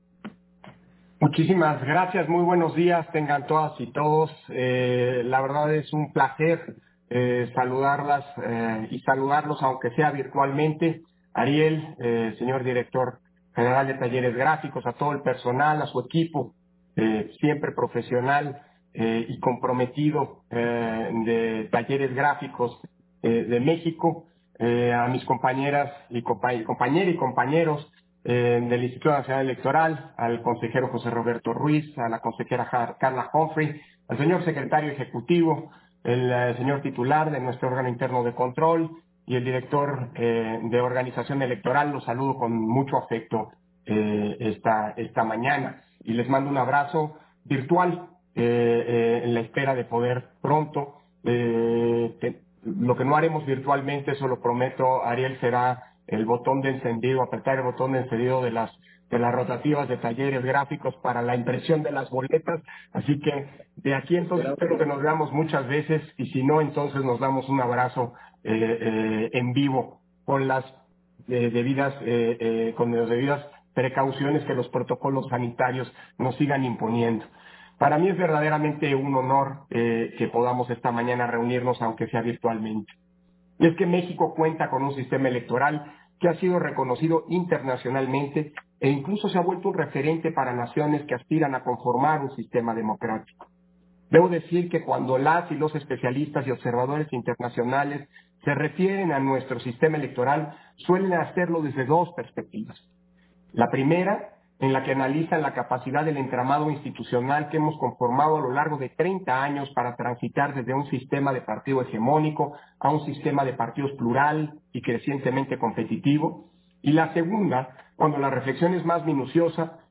Intervención de Lorenzo Córdova, en la firma del Convenio de Colaboración Técnica, INE-Talleres Gráficos de México